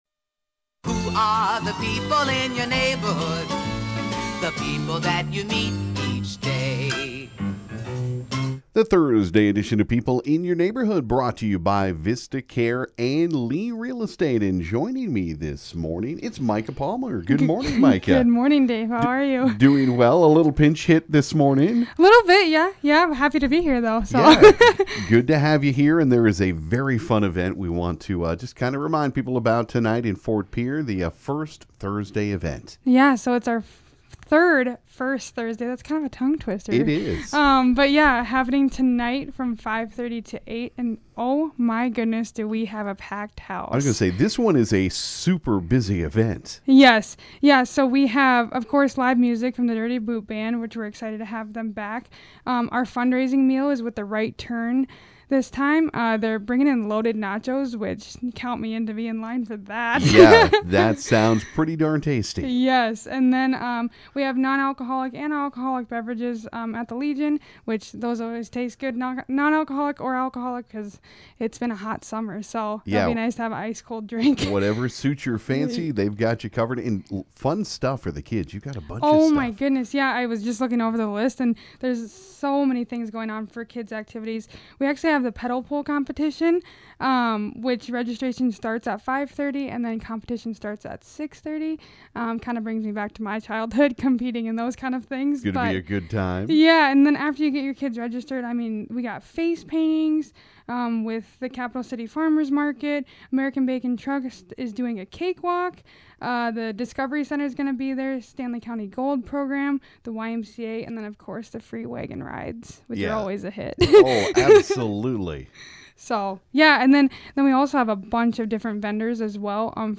stopped by the KGFX studio